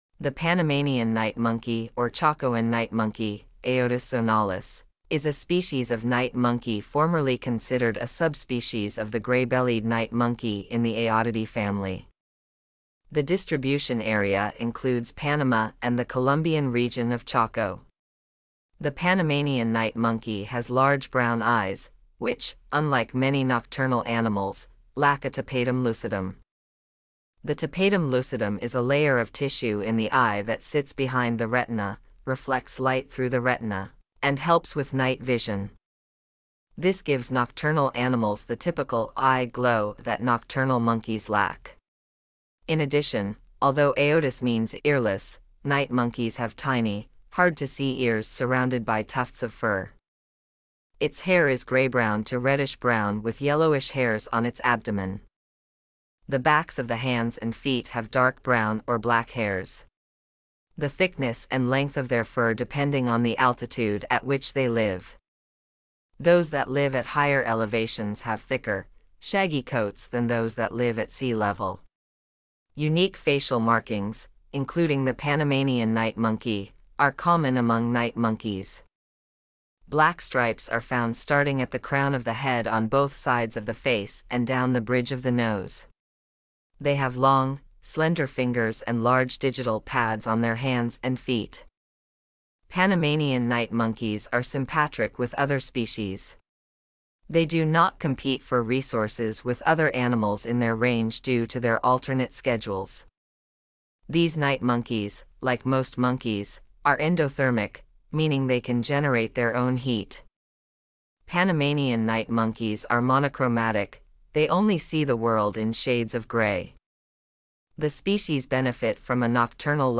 Panamanian-Night-Monkey.mp3